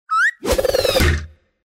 Звуки метания ножа
Вы можете слушать онлайн или скачать резкие, свистящие звуки полета и точные удары лезвия о деревянную мишень.
Мультяшное метание ножа